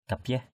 /ka-piah/ (d.) mũ phê (mũ lễ của nam giới đạo Hồi) = fez des musulmans. muslims fez.